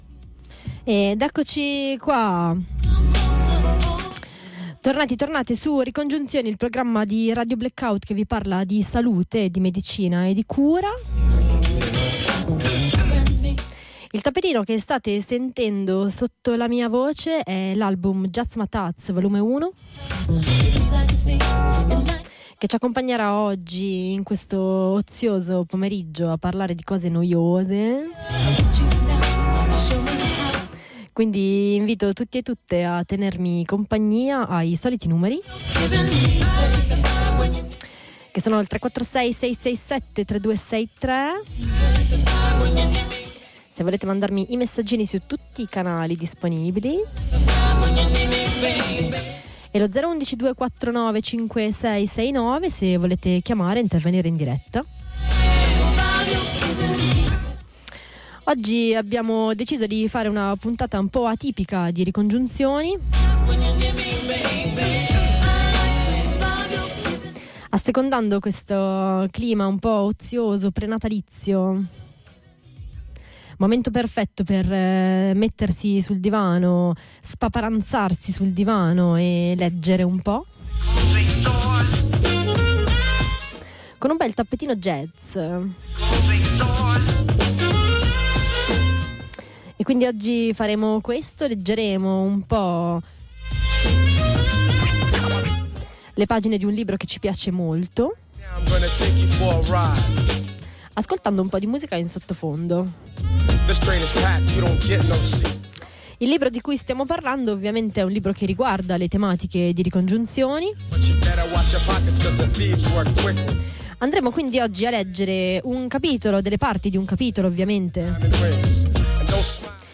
LETTURA DI NEMESI MEDICA DEL 20/12/2022
Per augurarvi un buon Natale una breve e concentrata lettura di alcuni estratti di Nemesi medica, di Ivan Illich. ri-congiunzioni-20.12.22